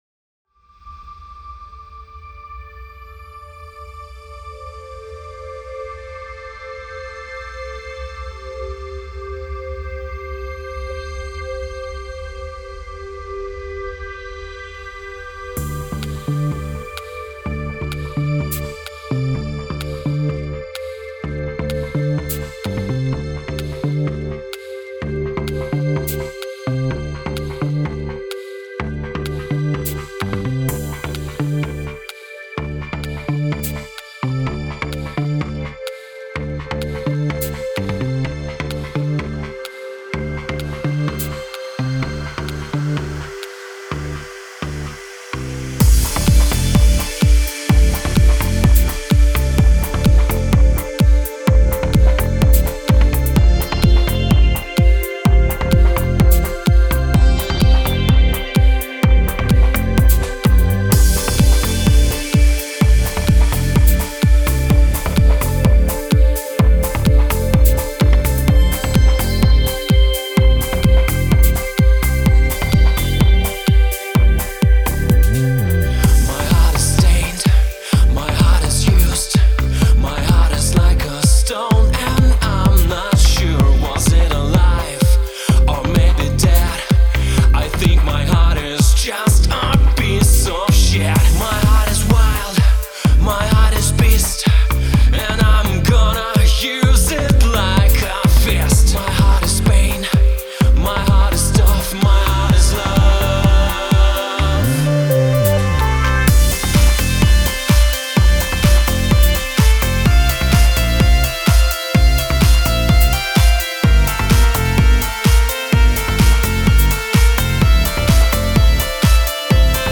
Покритикуйте EDM(видимо)